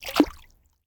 water-splash-12
bath bathroom bubble burp click drain dribble dripping sound effect free sound royalty free Nature